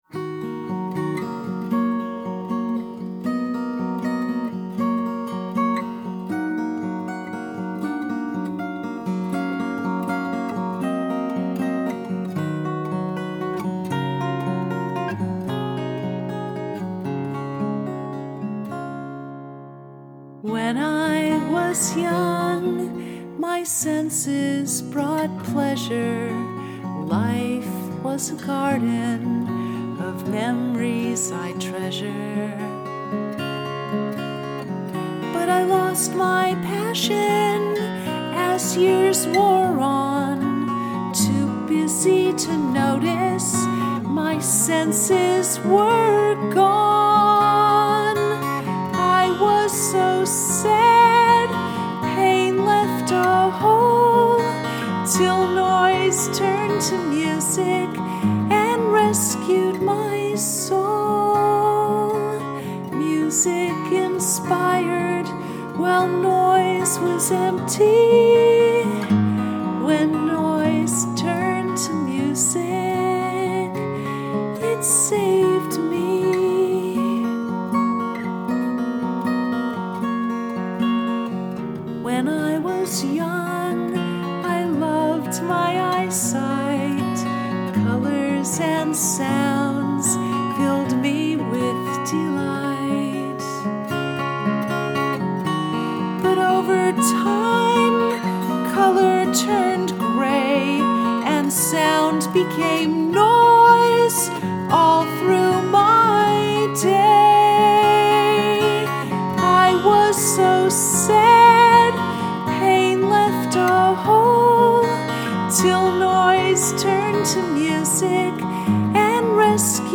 The blue link below plays a home recording: